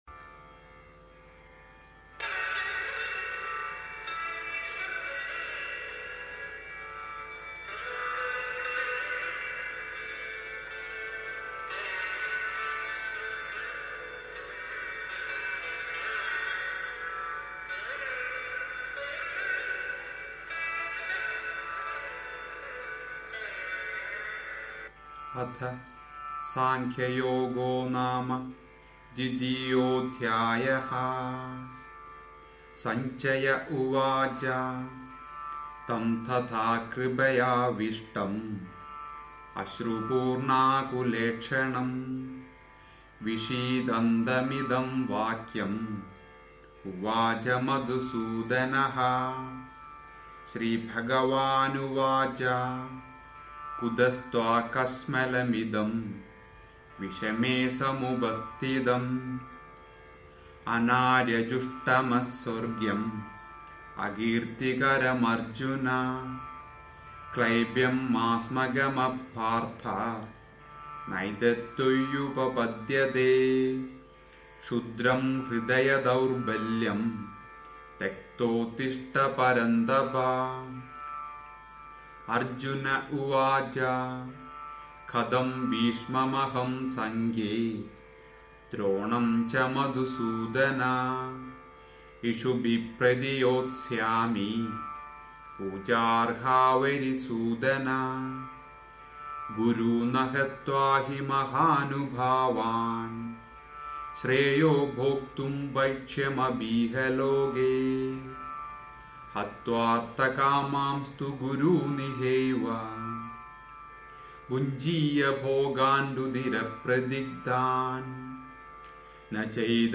Bhagavadgita Kapitel 2 Rezitation